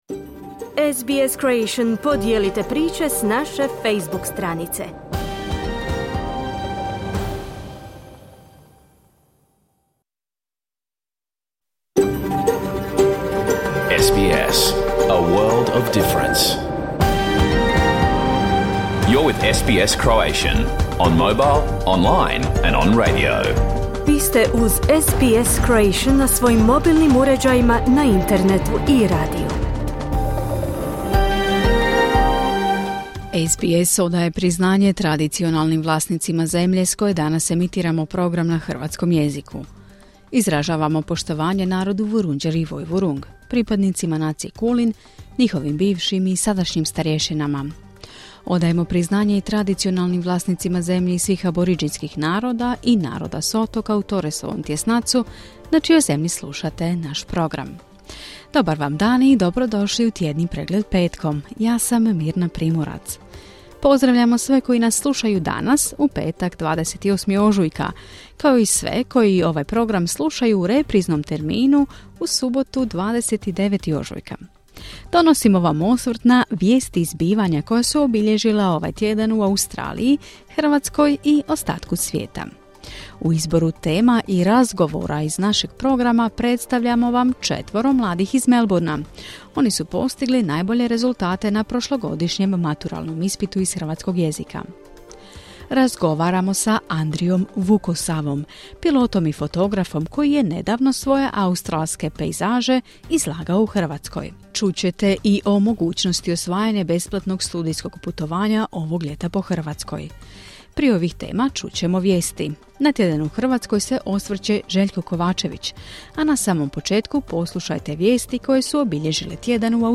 Vijesti, aktualne teme, analize i razgovori iz Australije, Hrvatske i ostatka svijeta.